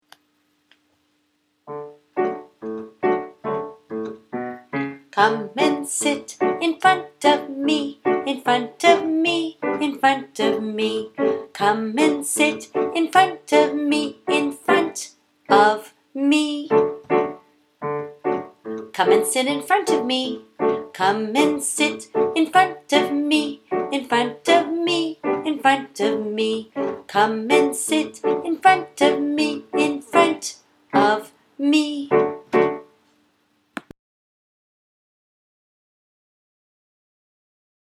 The lyrics for each of the transitional songs are included below with a simple recording.
(melody:  Muffin Man)